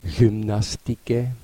klik op het woord om de uitspraak te beluisteren